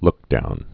(lkdoun)